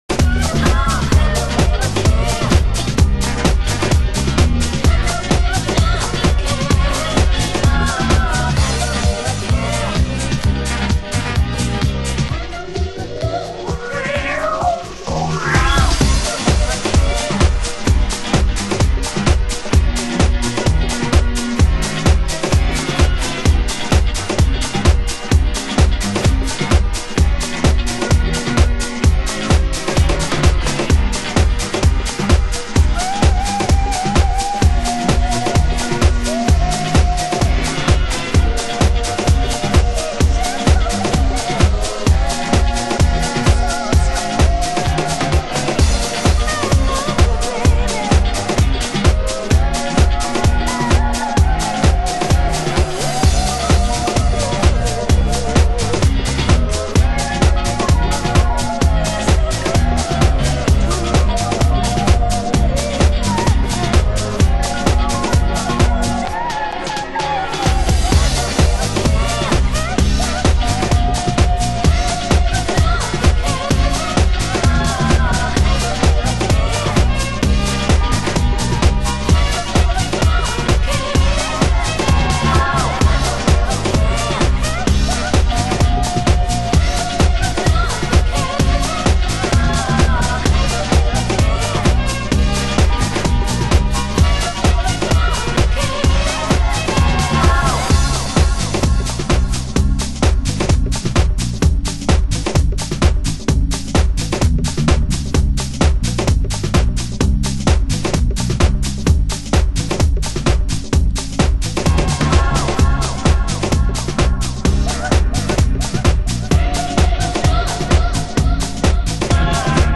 盤質：点在する小傷有/少しチリパチノイズ有